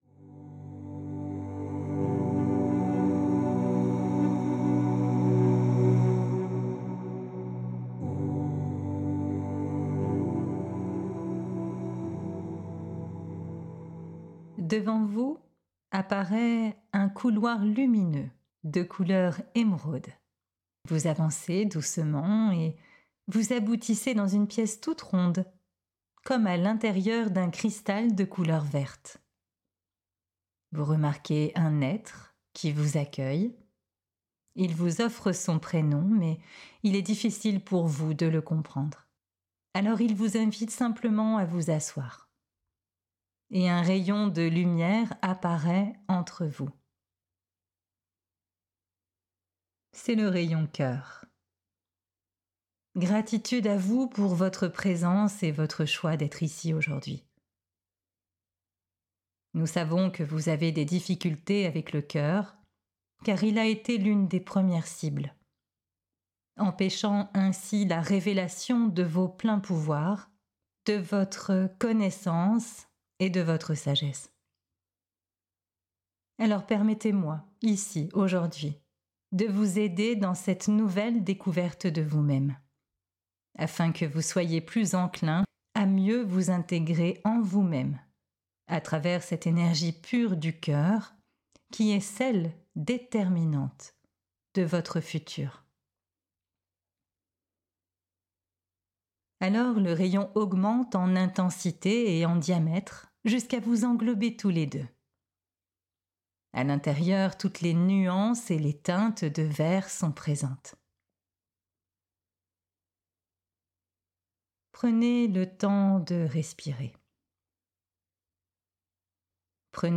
Dans cette méditation, un être de l'Agartha vous guide dans la purification des différents aspects de votre cœur, afin de pouvoir vous reconnecter au monde de l'Agartha, à sa sagesse cachée, et à votre vaisseau cœur.
Activation-reseau-Agartha-Meditation.mp3